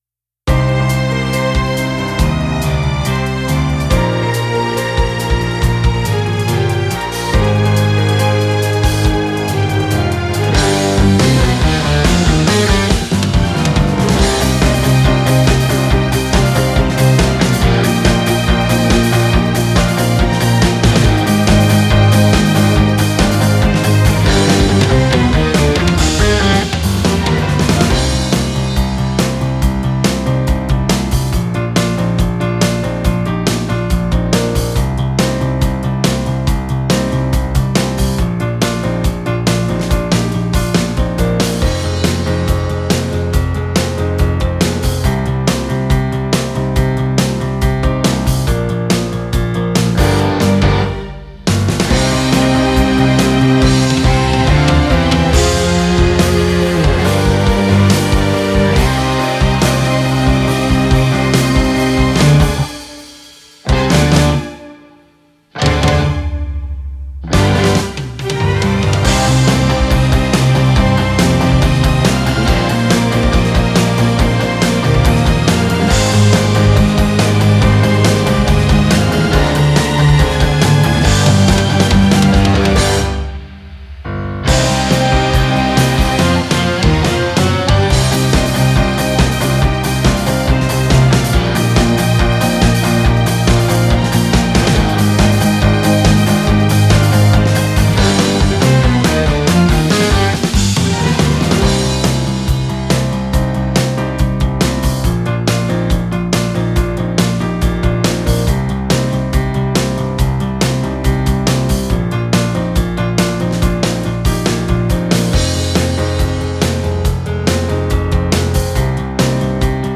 カラオケ音源
wav形式のカラオケ音源です。